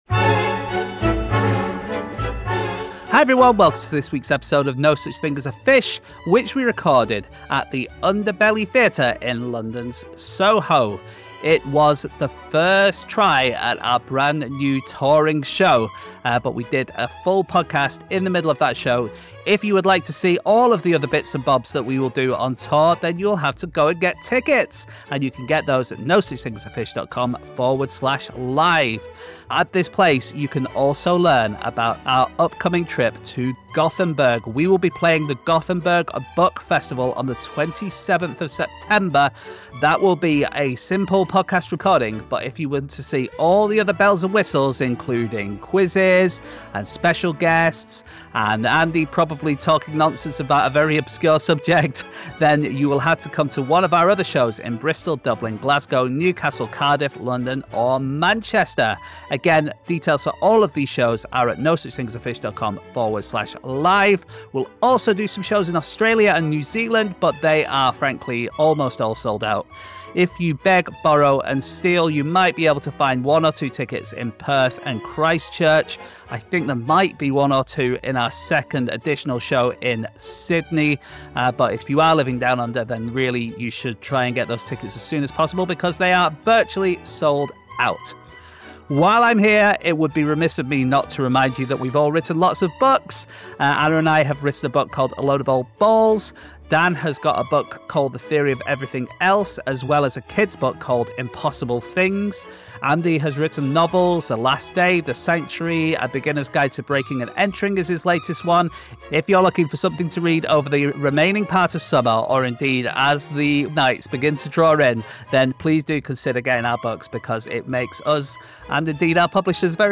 Live from the Underbelly in Soho